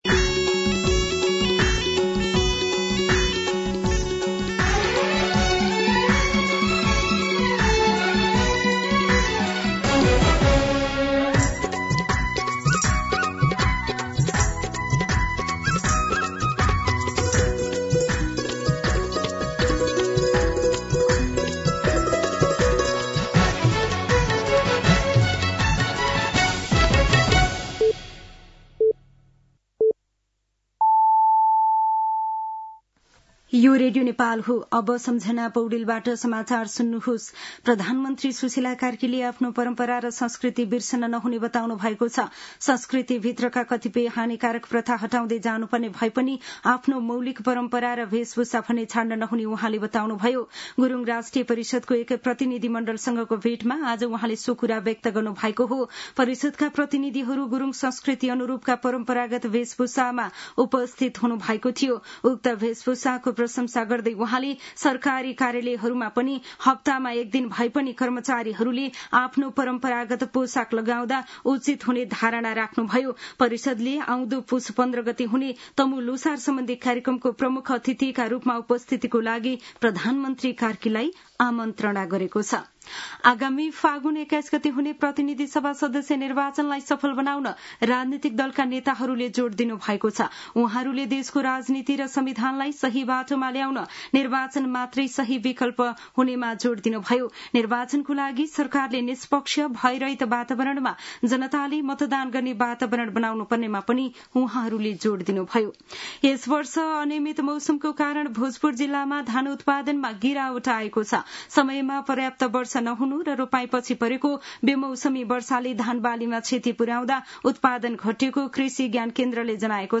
An online outlet of Nepal's national radio broadcaster
दिउँसो १ बजेको नेपाली समाचार : ६ पुष , २०८२